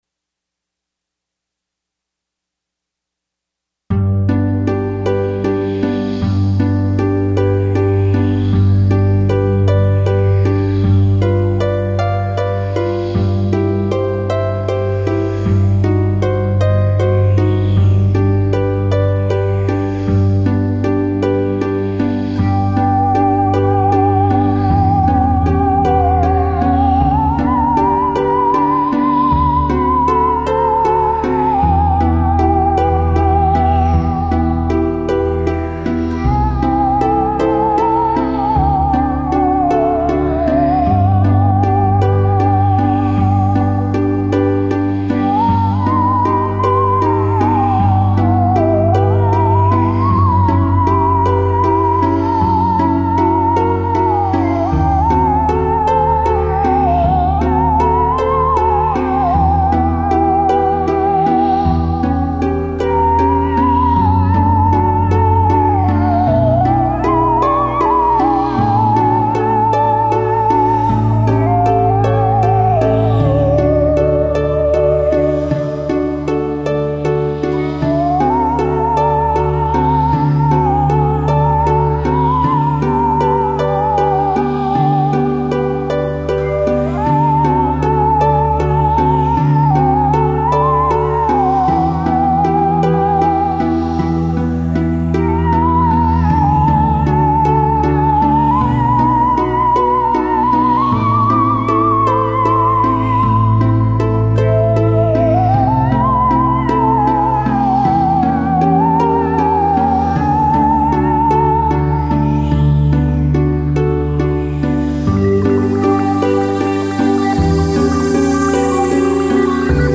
The songs are recorded with a MIDI sequencer.
4:13 - Adagio - 14 Aug 2003